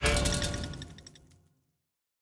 Minecraft Version Minecraft Version snapshot Latest Release | Latest Snapshot snapshot / assets / minecraft / sounds / block / trial_spawner / detect_player2.ogg Compare With Compare With Latest Release | Latest Snapshot